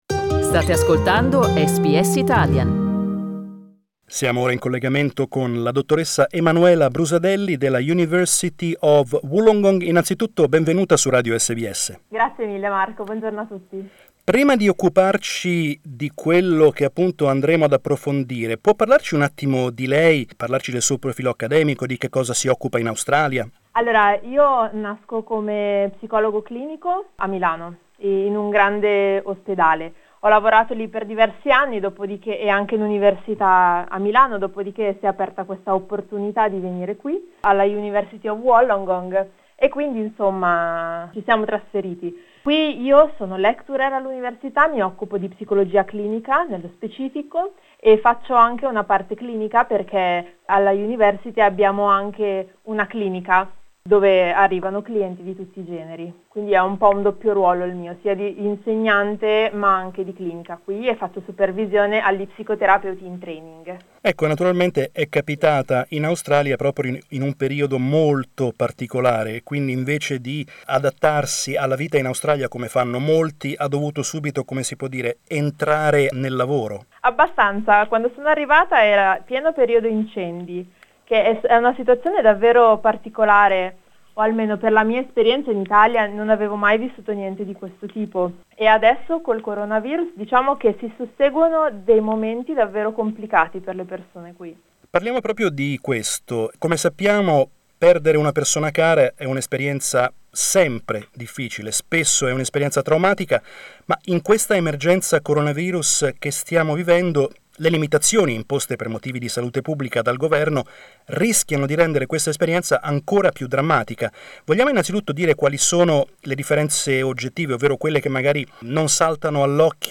We asked her how to manage this emergency also from a psychological point of view.